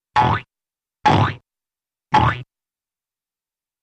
Object Bounces, 3 Slow, Low-pitched